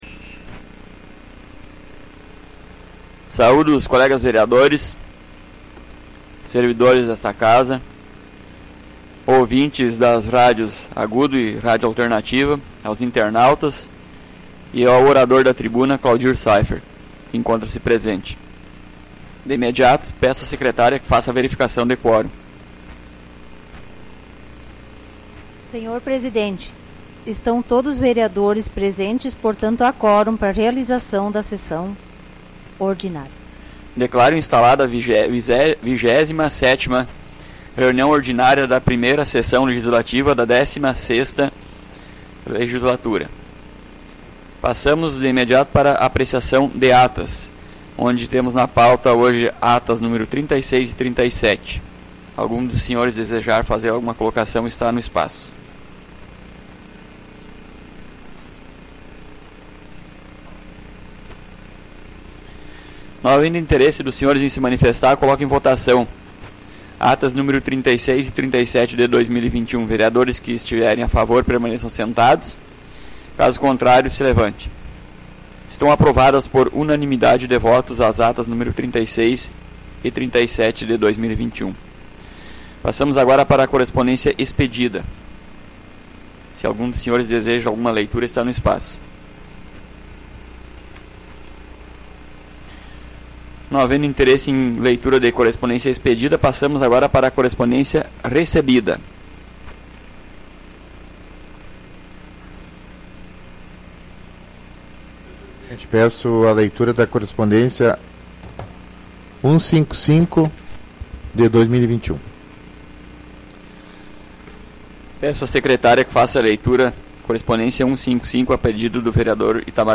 Áudio da 27ª Ordinária da 1ª Sessão Legislativa da 16ª Legislatura, de 6 de setembro de 2021